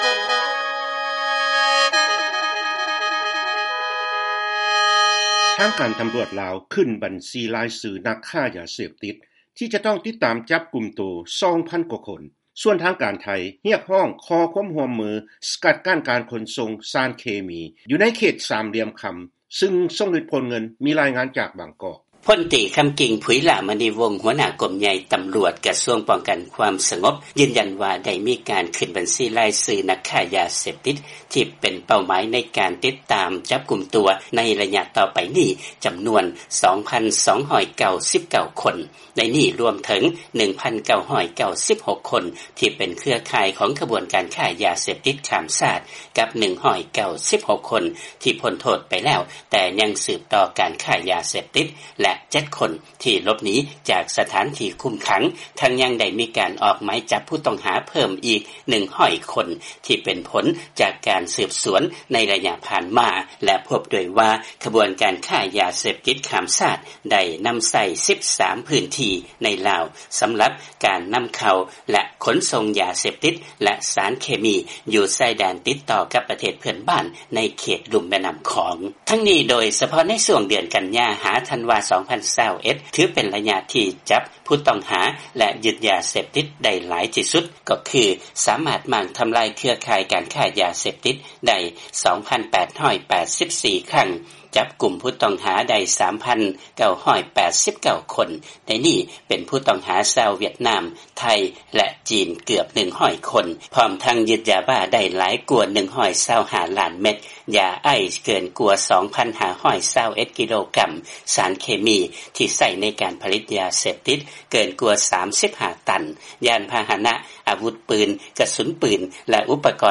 ເຊີນຟັງລາຍງານກ່ຽວກັບການປາບປາມຢາເສບຕິດຢູ່ລາວ ແລະເຂດລຸ່ມແມ່ນ້ຳຂອງ